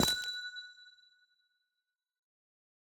Minecraft Version Minecraft Version latest Latest Release | Latest Snapshot latest / assets / minecraft / sounds / block / amethyst / step1.ogg Compare With Compare With Latest Release | Latest Snapshot